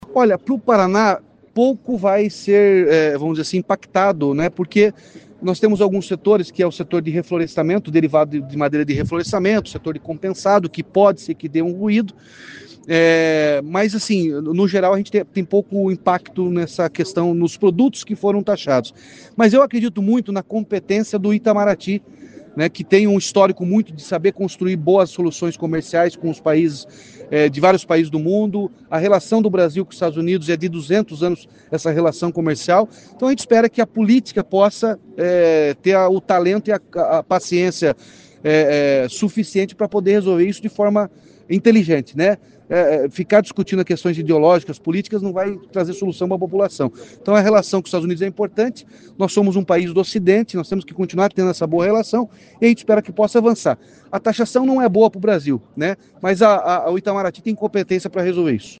Em entrevista coletiva nesta quinta-feira (17) em Paiçandu, o governador Ratinho Júnior falou sobre a taxação de 50% que os Estados Unidos querem cobrar em produtos importados do Brasil. O governador acredita numa resolução diplomática para a crise.